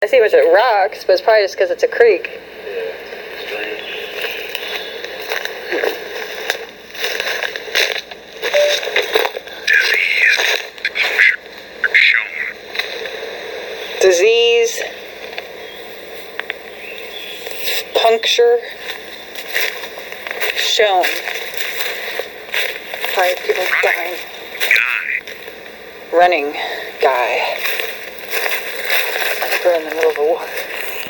These 2 audio clips are of the Ovilus. While we were hiking it appears we were picking up on Revolutionary War talk.